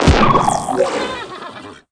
Block Horse Explosion Sound Effect
block-horse-explosion.mp3